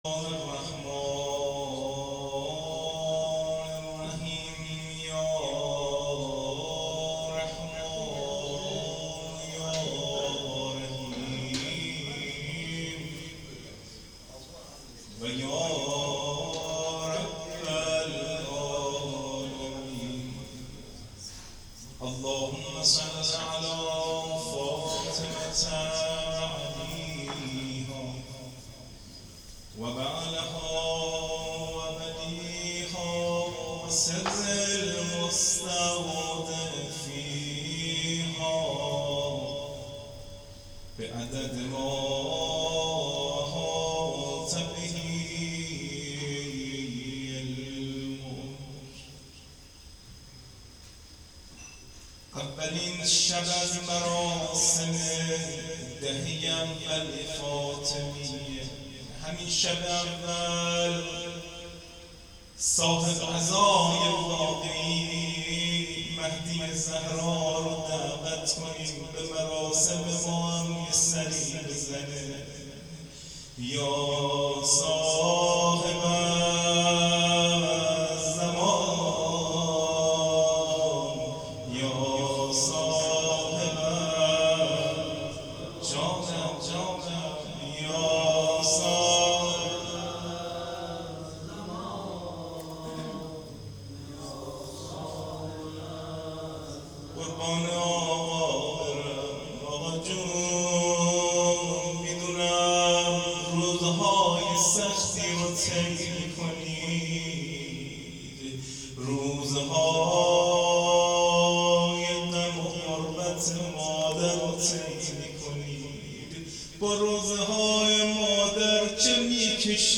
روضه شهادت حضرت زهرا س